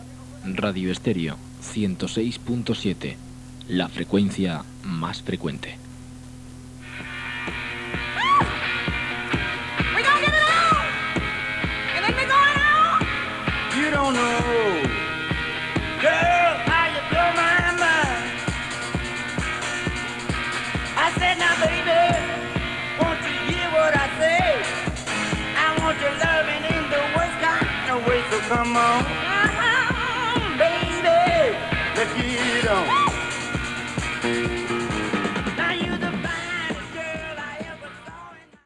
Identificació i tema musical.